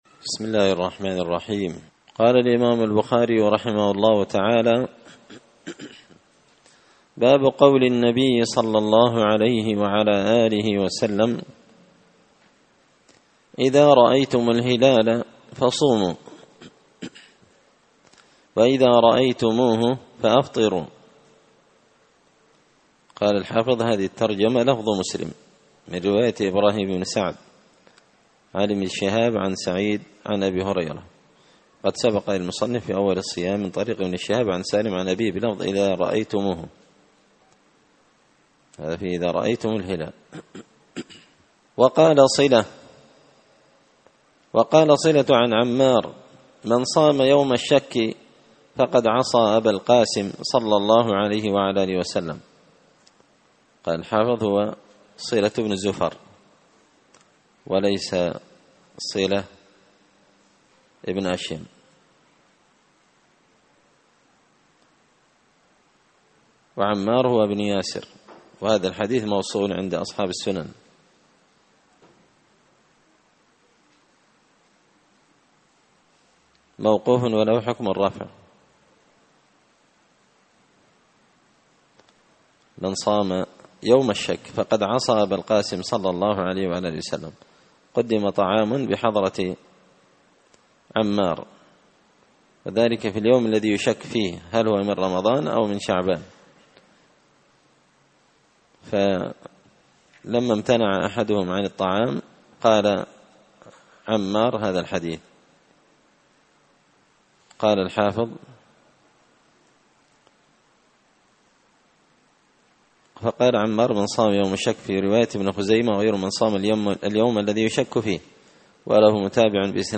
كتاب الصيام من صحيح البخاري الدرس العاشر (10) باب قول النبي صلى الله عليه وسلم إذا رأيتم الهلال فصوموا وإذا رأيتموه فأفطروا